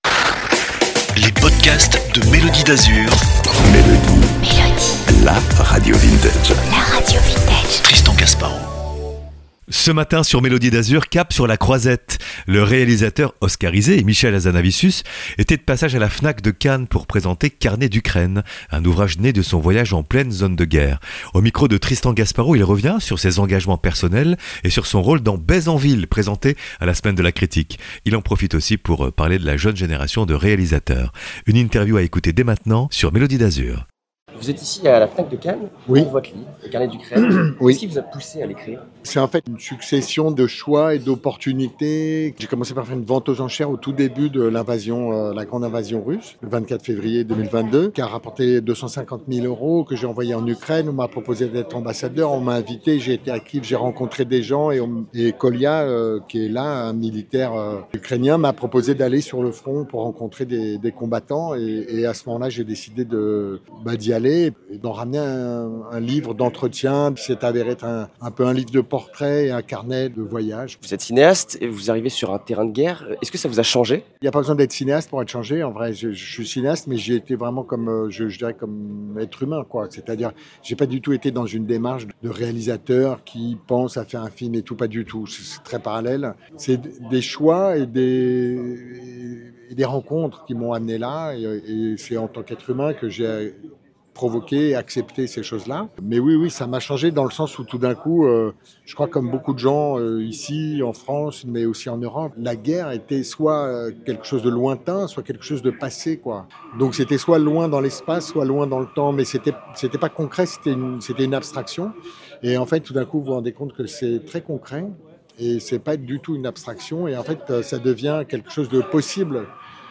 Michel Hazanavicius évoque l’Ukraine et la relève du cinéma au micro de Melody d’Azur. Une interview exclusive à écouter en ligne.